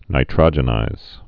(nī-trŏjə-nīz, nītrə-jə-)